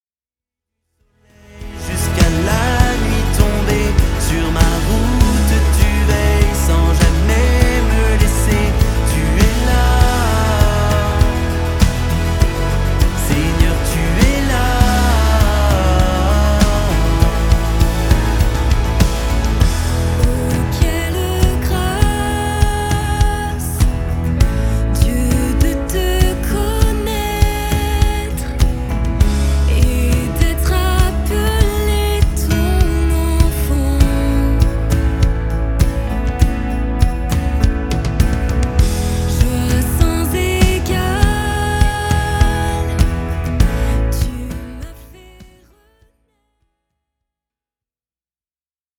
louanges